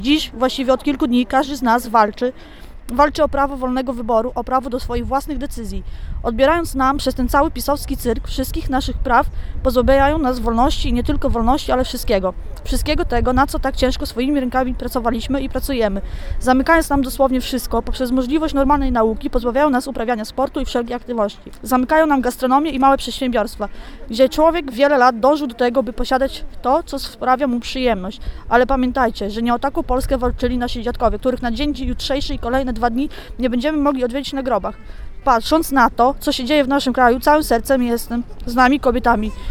Tradycyjnie nie zabrakło też głosu młodego pokolenia, które na bunt przeciw rządowi spogląda z szerszej perspektywy.